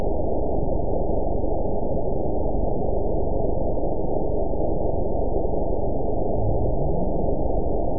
event 922587 date 02/06/25 time 03:57:11 GMT (4 months, 1 week ago) score 8.97 location TSS-AB02 detected by nrw target species NRW annotations +NRW Spectrogram: Frequency (kHz) vs. Time (s) audio not available .wav